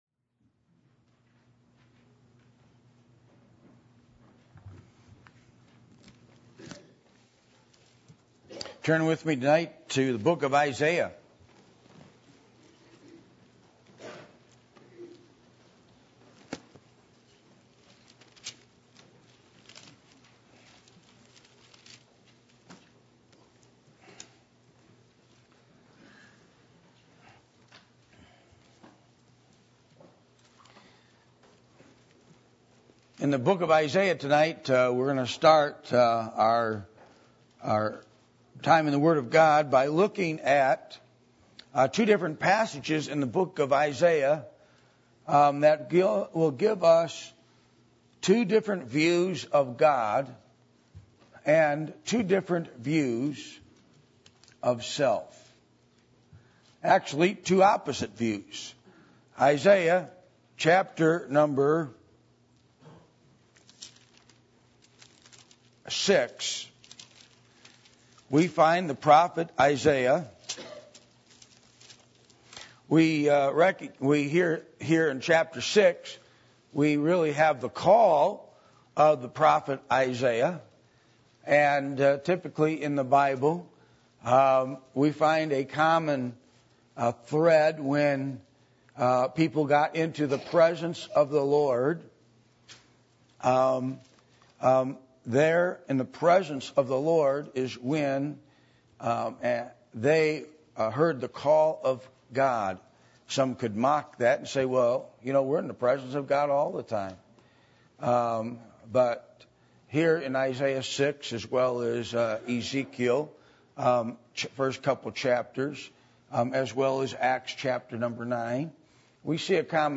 Passage: Isaiah 6:1-5, Isaiah 14:12-14 Service Type: Sunday Evening